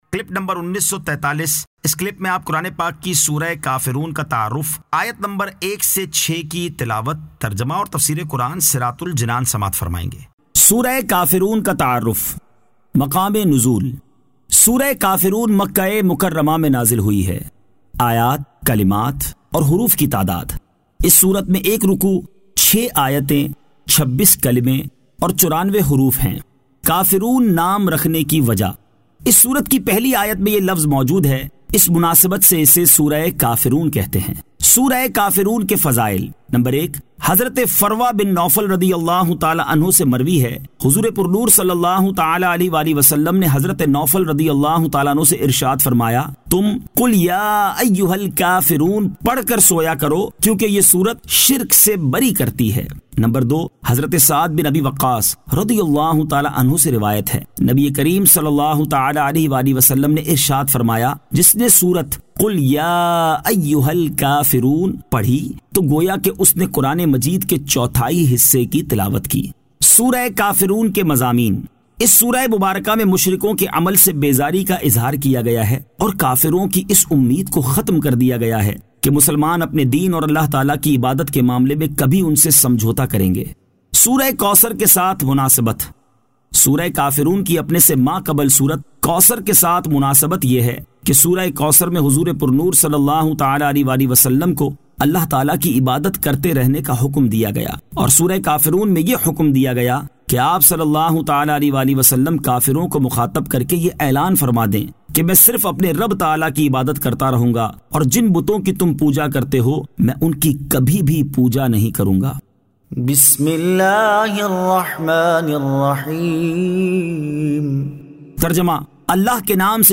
Surah Al-Kafiroon 01 To 06 Tilawat , Tarjama , Tafseer